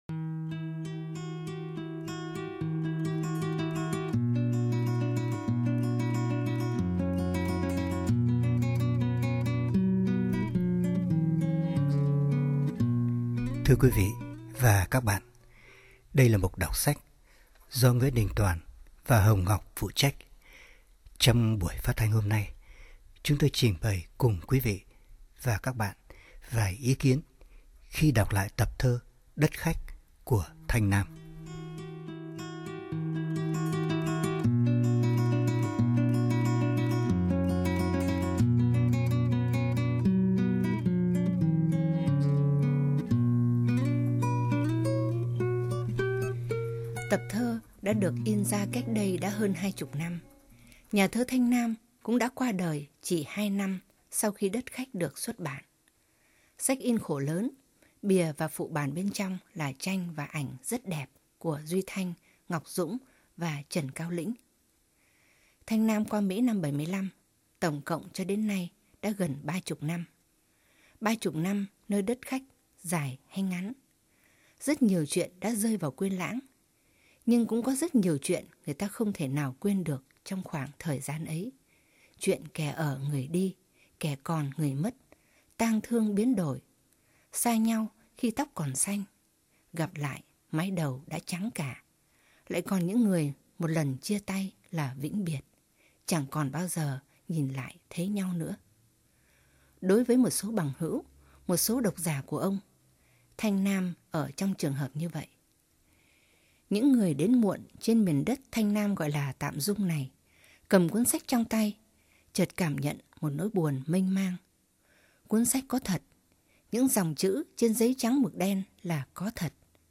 Giọng NĐT không còn là giọng một thanh niên ủ rũ héo sầu ẩm ướt sương buồn tới nỗi sém nhão nhẹt, và những lời ông viết không còn là những lời diễm ảo cho tới nỗi sém cải lương. Phải nghe mới biết, NĐT như một ca sĩ biết truyền cảm xúc cho người nghe khi hát, ở cái cách nhấn chữ nhả câu, ở những khoảng cắt ngừng hay lơi giọng, đây _ một người đàn ông trải đời với hồn cảm nhận thâm sâu, nói những lời đau mong với tới muôn ngàn những bóng người vô danh trong cuộc sống.